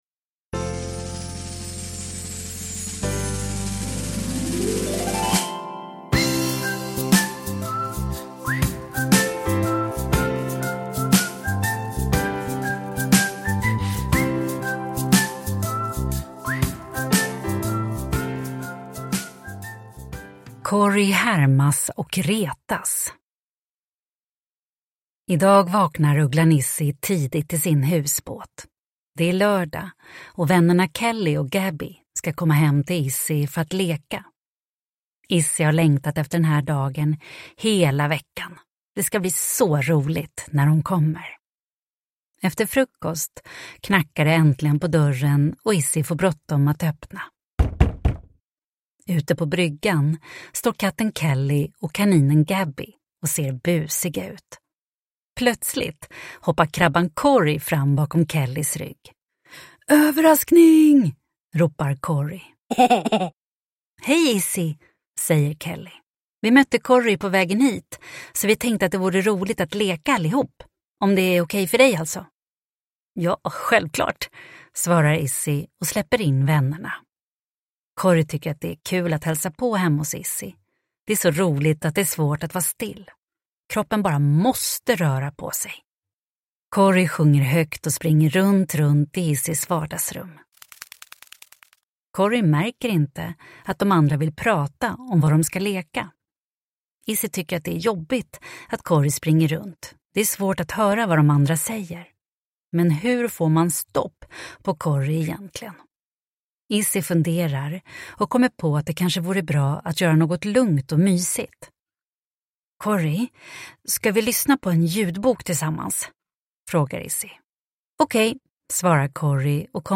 Cory härmas och retas – Ljudbok – Laddas ner